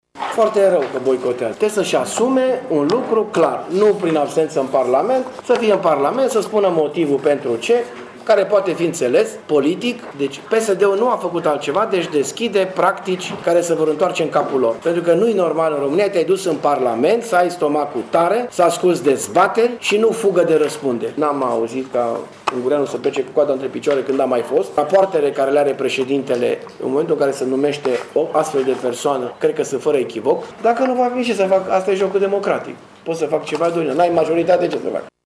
Așa a afirmat azi, într-o conferință de presă, co-preşedintele PNL Mureş, Dorin Florea.
Florea a spus că social-democraţii trebuie să meargă la votul de după-amiază şi să-şi asume poziţia faţă de numirea preşedintelui Iohannis: